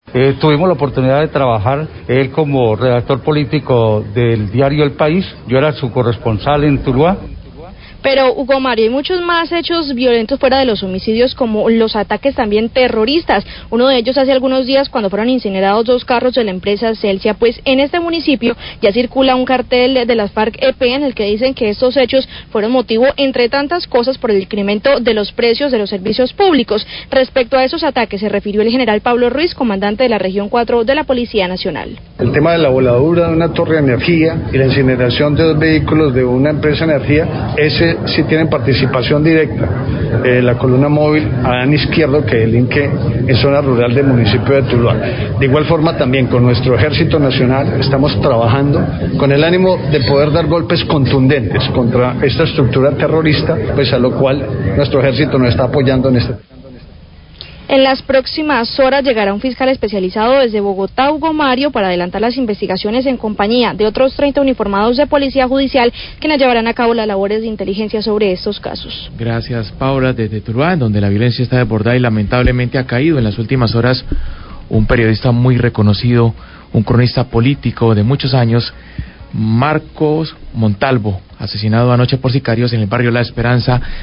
Radio
El comandante de la Región No.4 de la Policía Nacional, general Pablo Ruíz, habla de la voladura de torre de energía y el posterior ataque a dos vehículos de Celsia por parte del frente Adán Izquierdo de la disidencias e las FARC. La periodista informa que las disidencias hacen circular un cartel en Tuluá donde adjudica la quema de los dos vehículos al incremento del costo del servicio de energía.